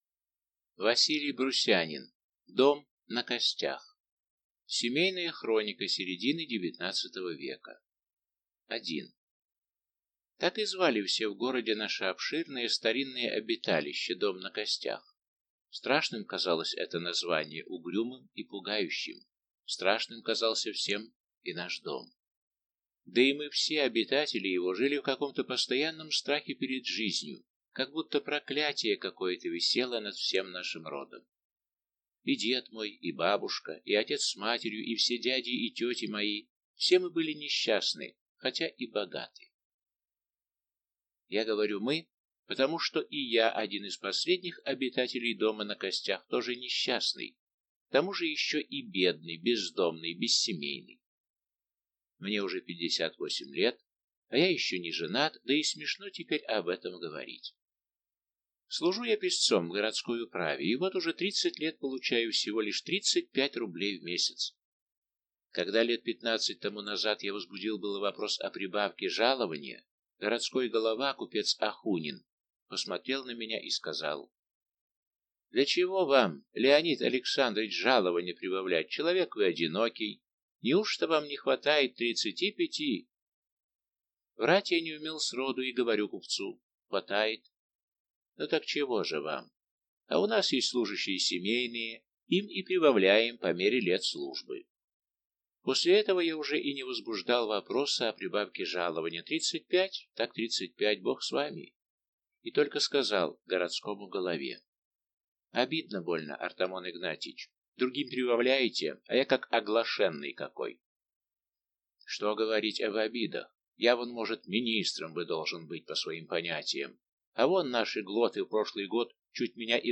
Аудиокнига Дом на костях | Библиотека аудиокниг